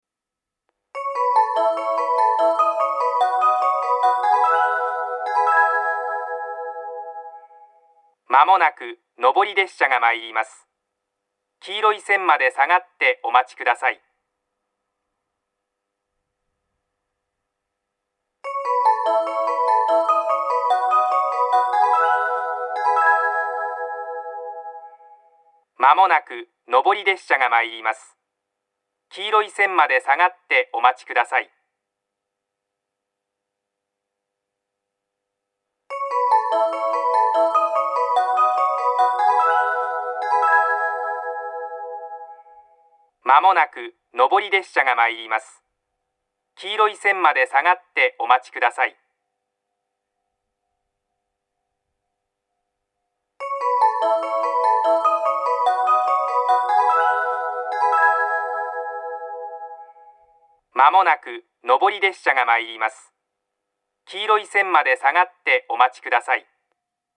minami-fukushima-1bannsenn-sekkinn.mp3